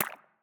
SFX_Slime_Hit_02.wav